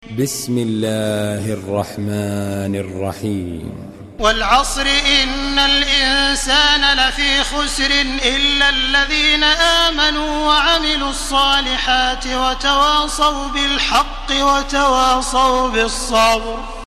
Surah العصر MP3 by تراويح الحرم المكي 1431 in حفص عن عاصم narration.
مرتل